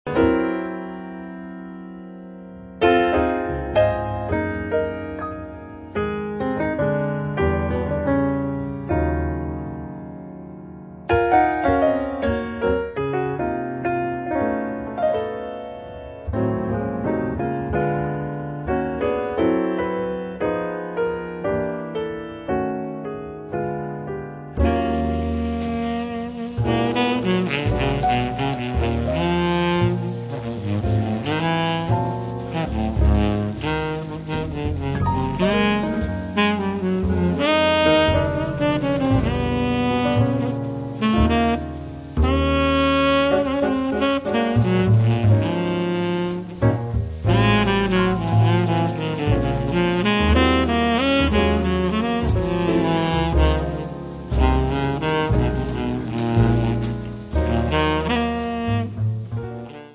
piano
basso
batteria
sax tenore
Tromba